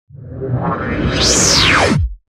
دانلود صدای ربات 56 از ساعد نیوز با لینک مستقیم و کیفیت بالا
جلوه های صوتی